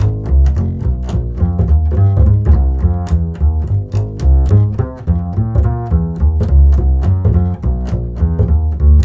-JP WALK G#.wav